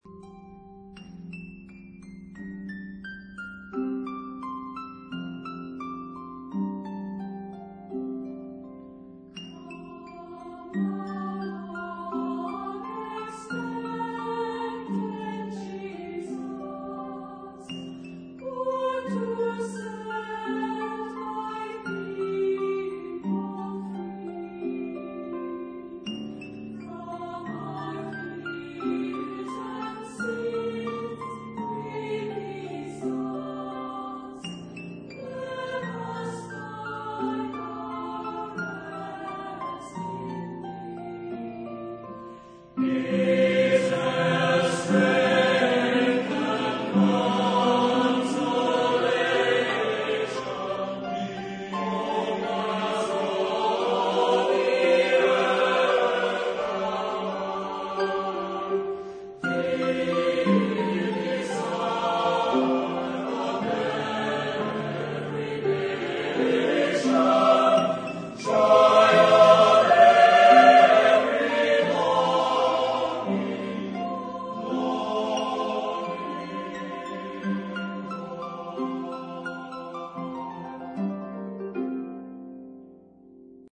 Genre-Style-Forme : Sacré ; noël ; Carol ; Hymne (sacré)
Type de choeur : SATBB  (5 voix mixtes )
Instrumentation : Ensemble instrumental
Instruments : Cordes ; Bois
Tonalité : modal ; fa majeur